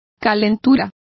Complete with pronunciation of the translation of temperature.